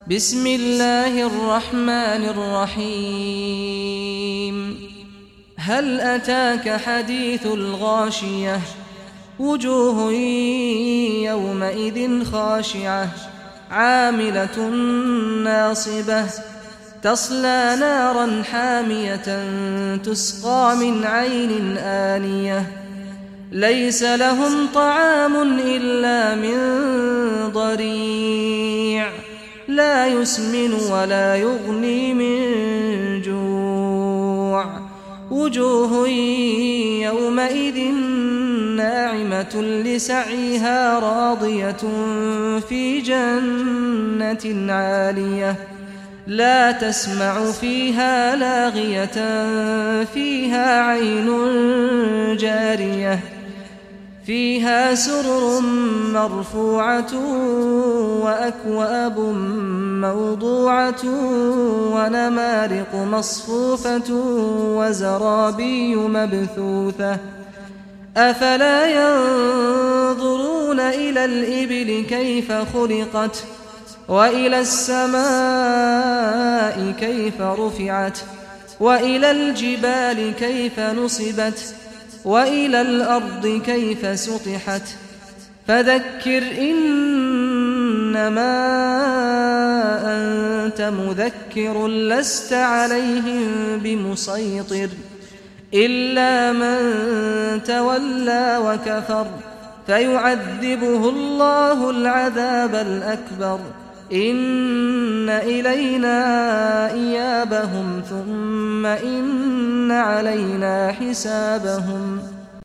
Surah Al-Ghashiyah Recitation by Saad al Ghamdi